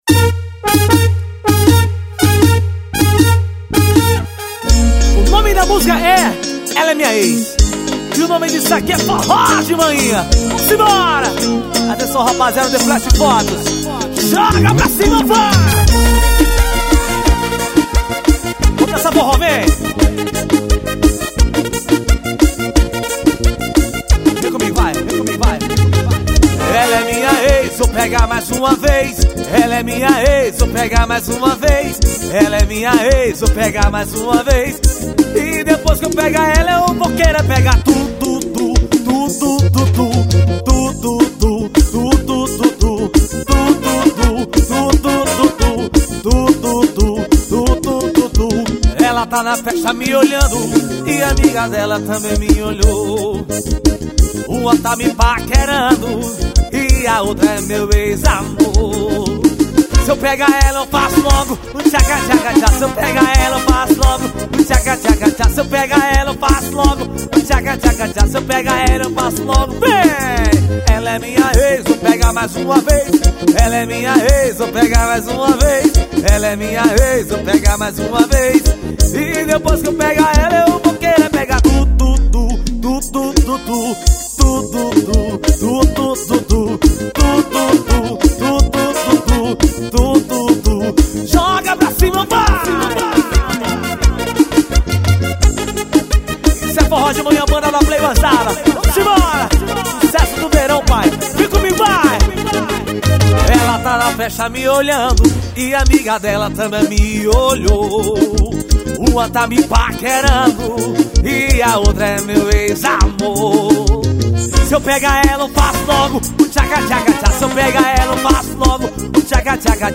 forro pegado.